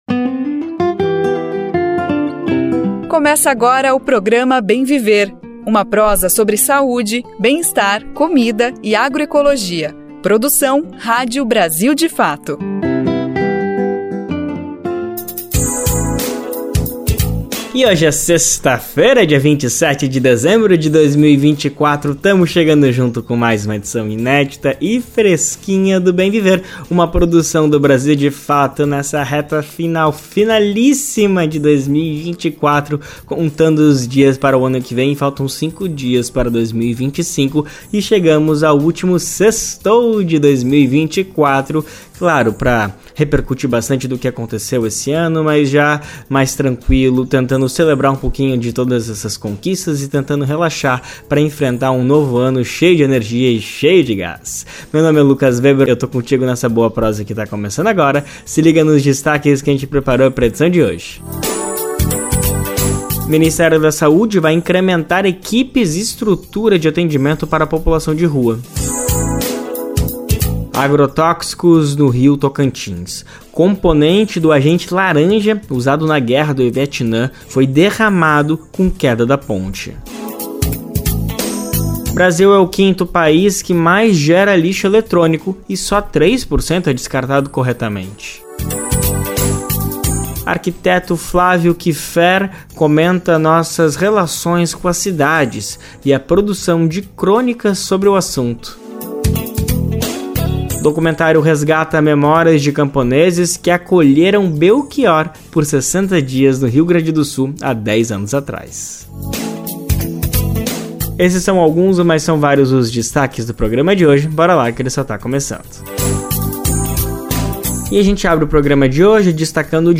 Nesta sexta-feira (27), o programa Bem Viver, produzido pelo Brasil de Fato, aborda assuntos que vão do direito à saúde das pessoas em situação de rua até a relação das cidades com as mudanças climáticas. A edição traz uma entrevista exclusiva com Felipe Proenço, secretário de Atenção Primária à Saúde do Ministério da Saúde.